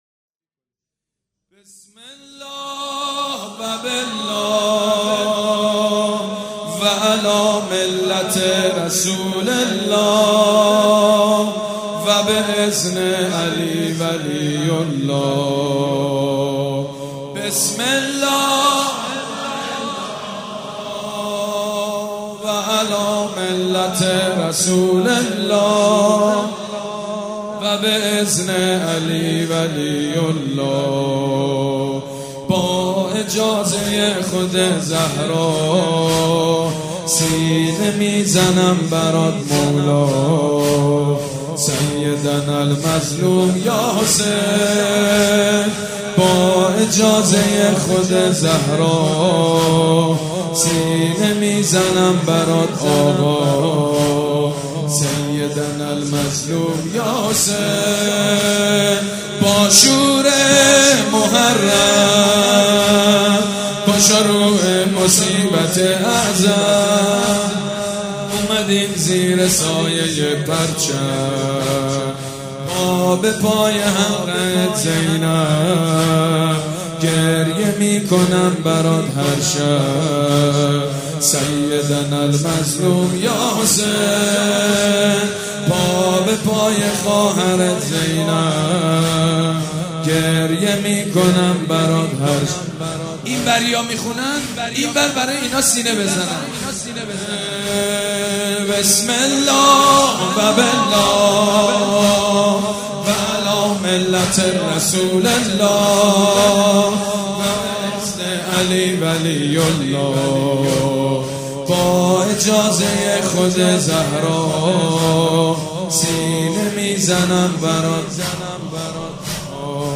شب ششم محرم الحرام‌ جمعه ۱6 مهرماه ۱۳۹۵ هيئت ريحانة الحسين(س)
مداح حاج سید مجید بنی فاطمه
مراسم عزاداری شب ششم